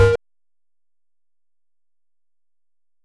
Snare (Say You Will).wav